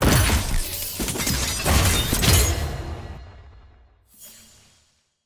sfx-tier-wings-promotion-to-silver.ogg